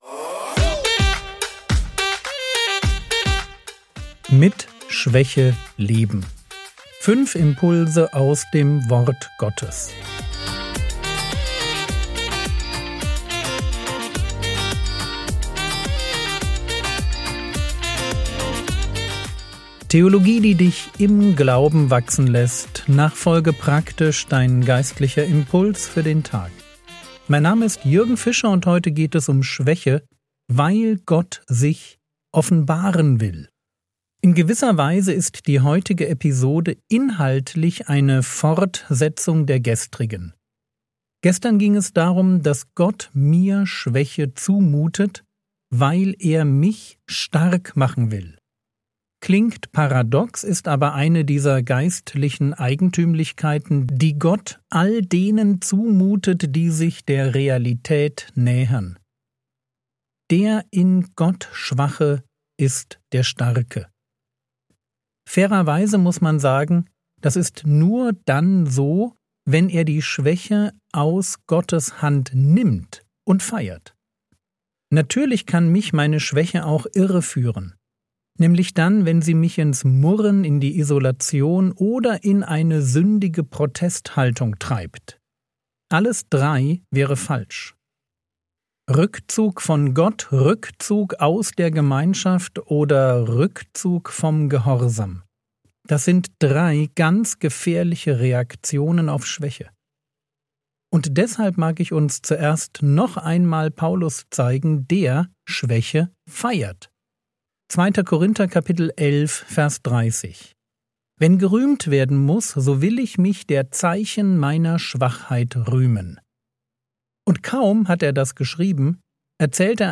Mit Schwäche leben (4/5) ~ Frogwords Mini-Predigt Podcast